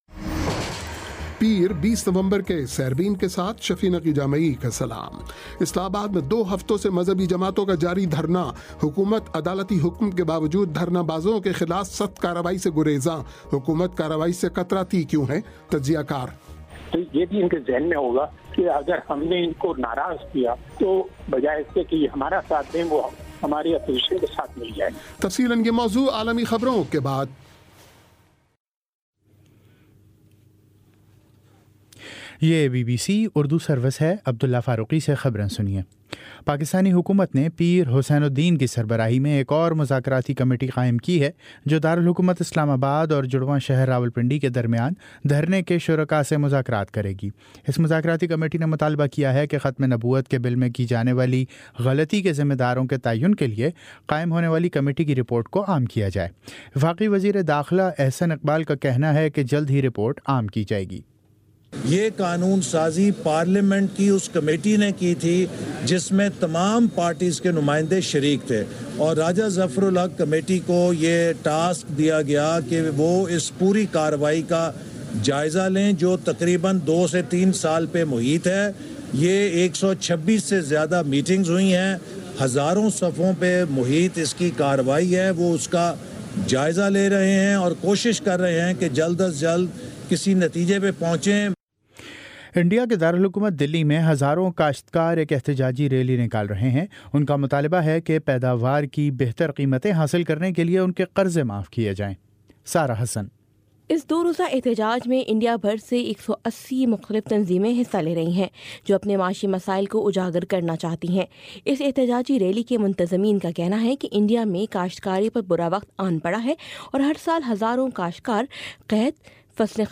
پیر 20 نومبر کا سیربین ریڈیو پروگرام